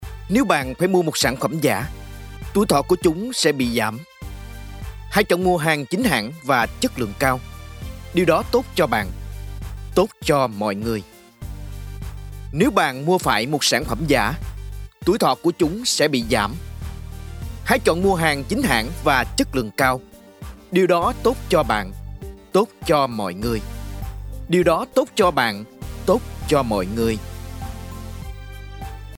Locutores vietnamitas. Locutores y locutoras de Vietnam
locutor Vietnam, Vietnam voice over